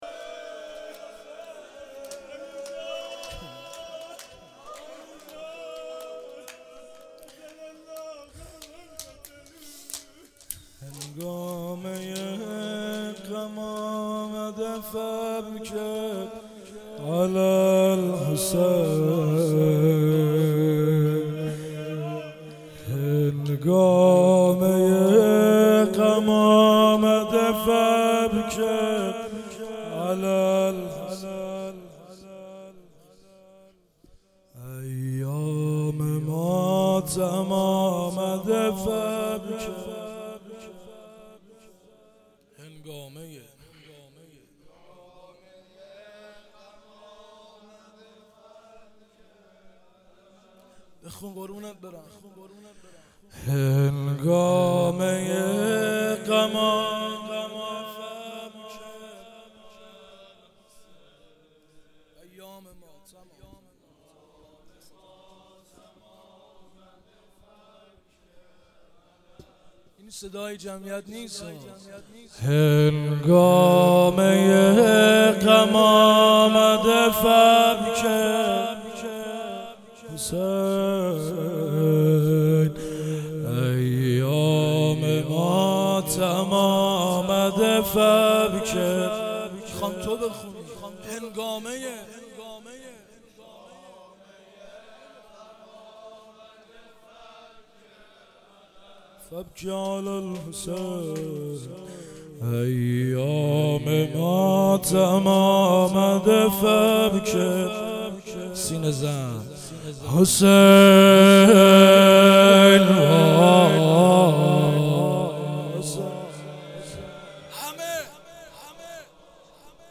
محرم1393